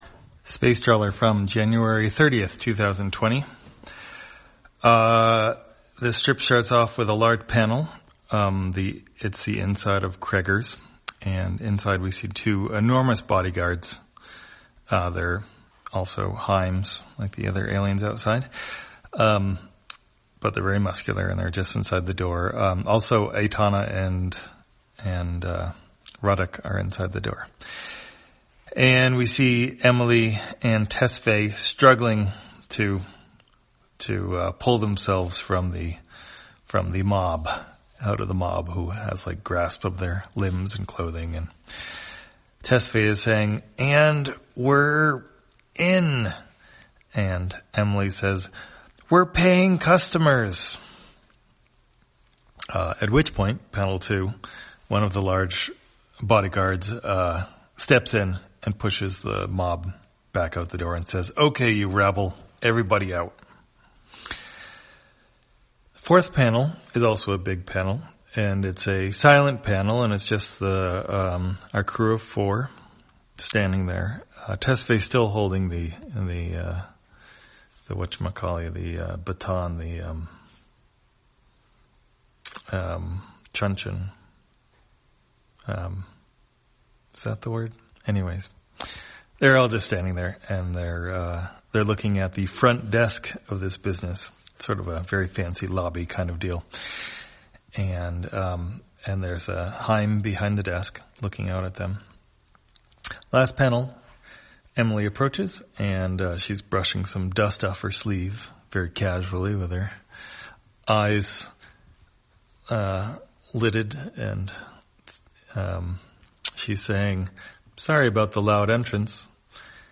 Spacetrawler, audio version For the blind or visually impaired, January 30, 2020.